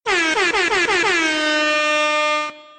MLG Air Horn – Quieter
Category Pranks
MLG-Air-Horn-Quieter.mp3